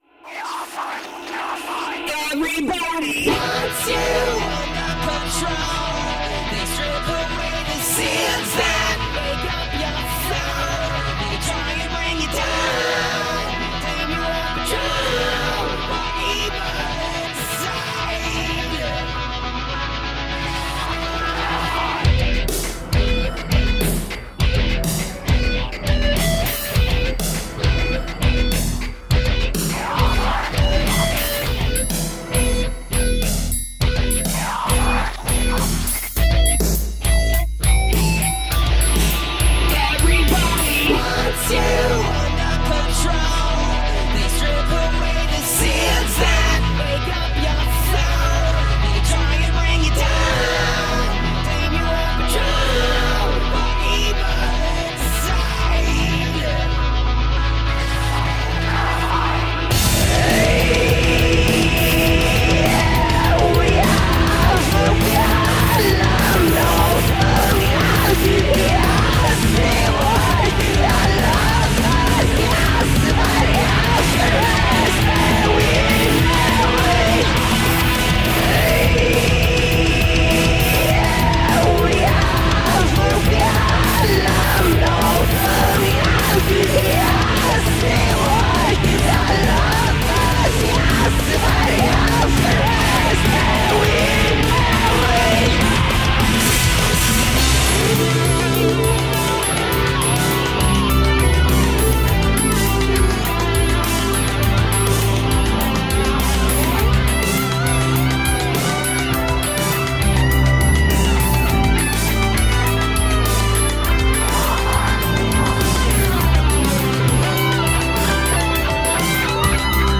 Guitar-edged industrial electrorock.